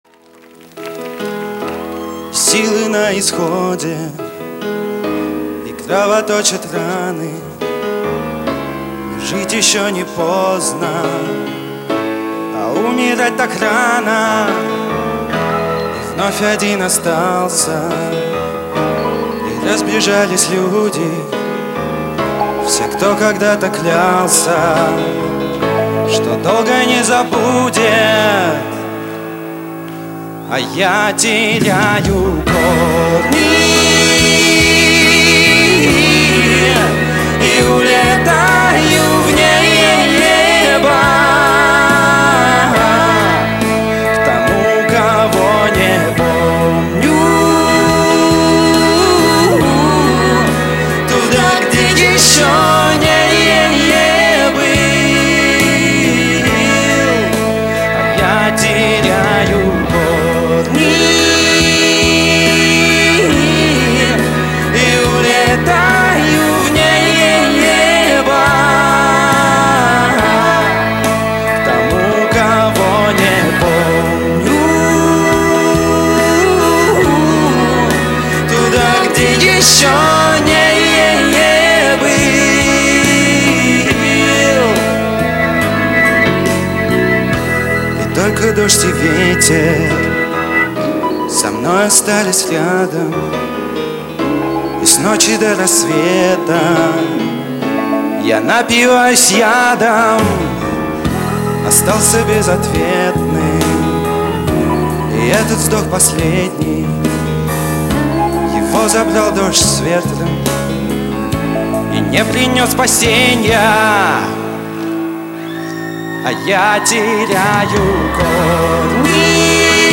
Запись была сделана мной с Тюнера AverMedia TV-Phon